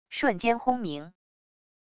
瞬间轰鸣wav音频